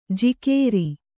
♪ jikēeri